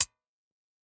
Index of /musicradar/ultimate-hihat-samples/Hits/ElectroHat D
UHH_ElectroHatD_Hit-26.wav